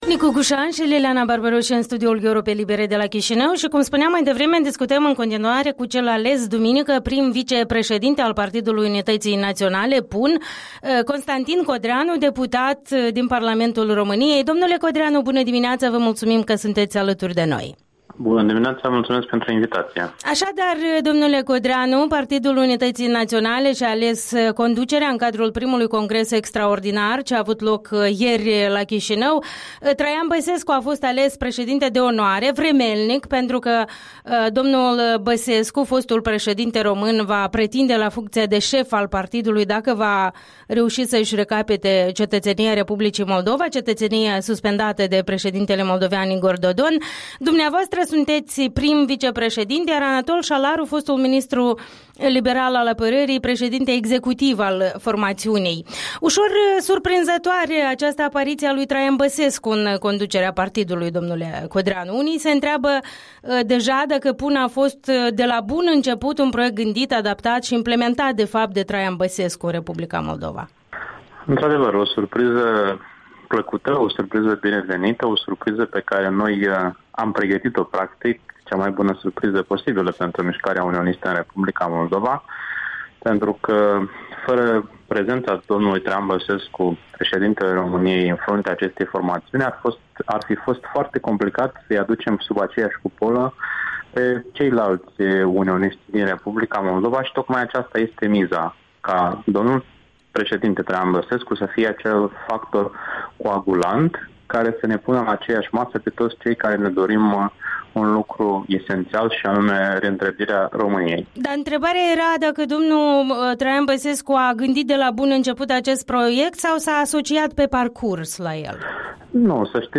Interviul dimineții cu prim-vicepreședintele Partidului Unității Naționale.